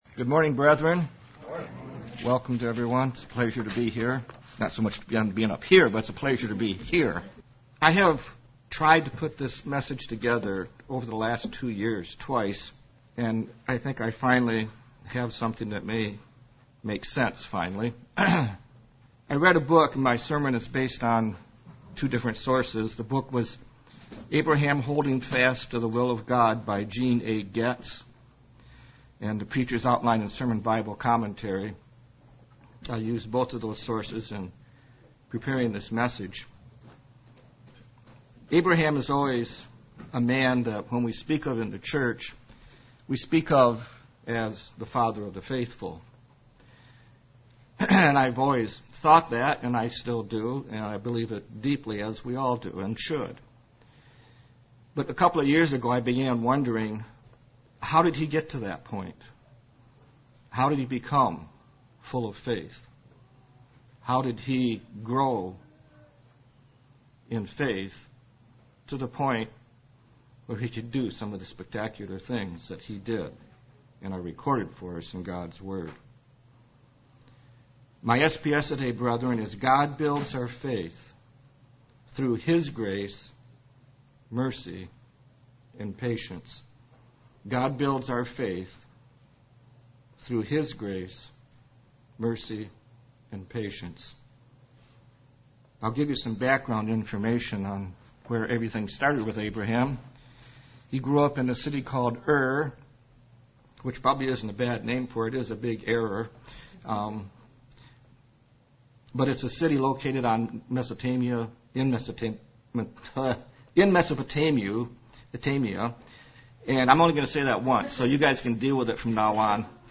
Sermons
Given in Ann Arbor, MI